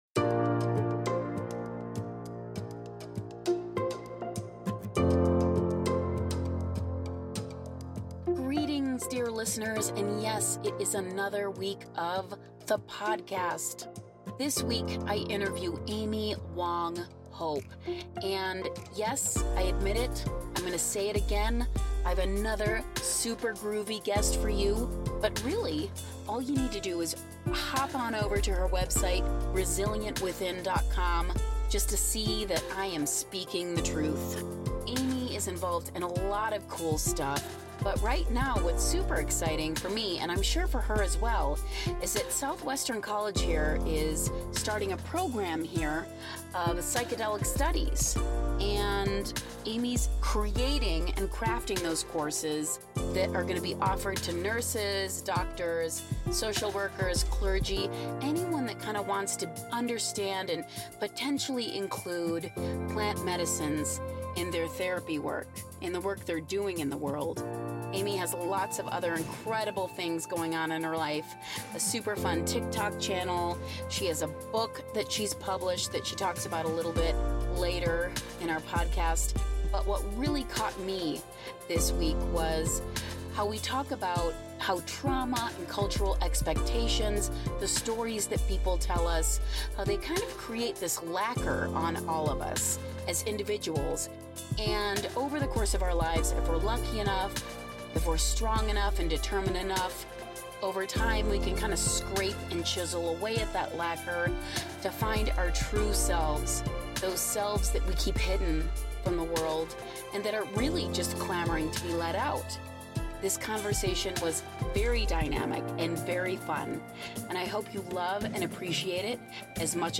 The Interview